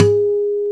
Index of /90_sSampleCDs/Roland L-CDX-01/GTR_Steel String/GTR_ 6 String
GTR 6-STR312.wav